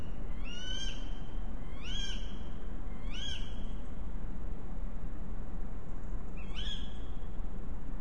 Geese 18/08/22 23:52